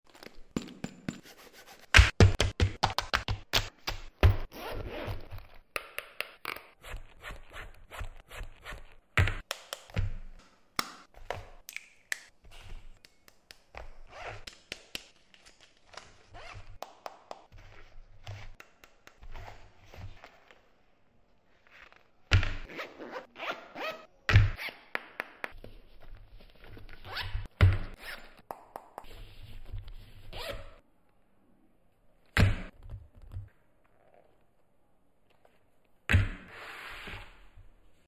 ASMR Nova handbag! Handbag sekecil ini bisa muat banyak loh sob!